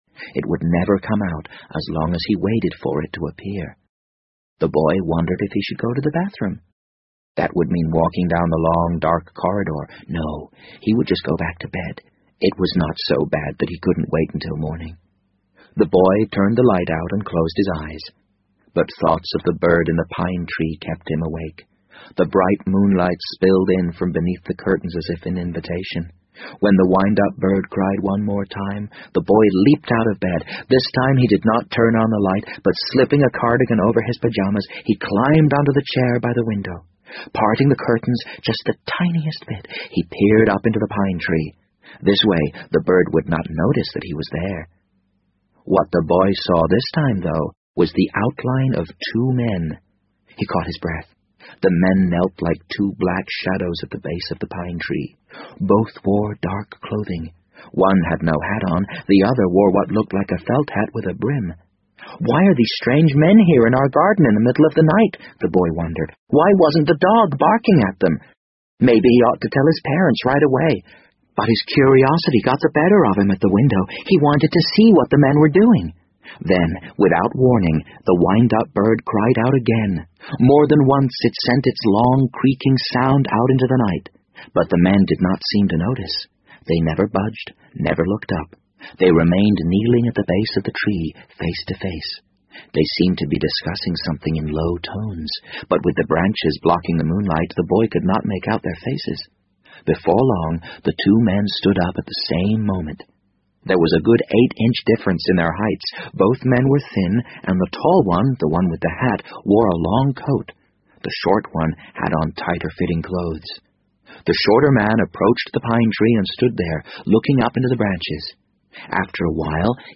BBC英文广播剧在线听 The Wind Up Bird 009 - 14 听力文件下载—在线英语听力室